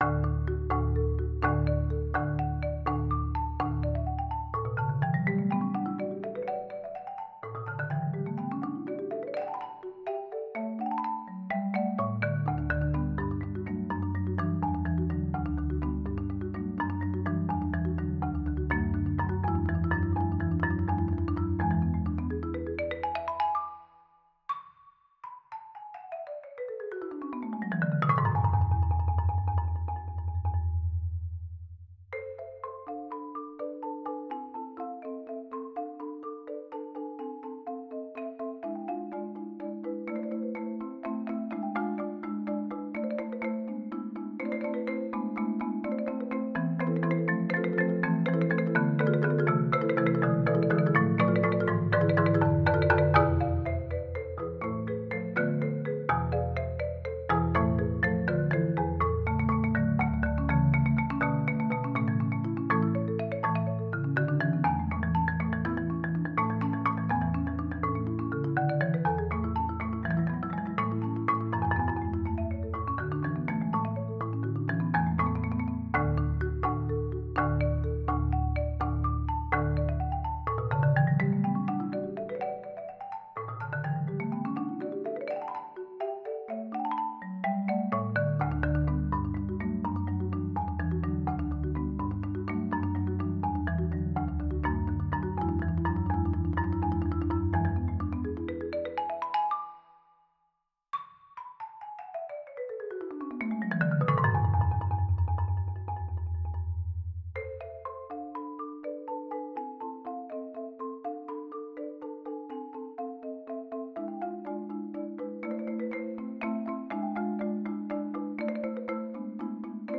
Voicing: Marimba Duet